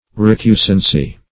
Recusancy \Re*cu"san*cy\ (r?*k?"zan*s? or r?k"?-), n.